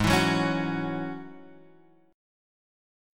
G#7sus4#5 chord